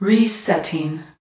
bl_voice_ball_reset.ogg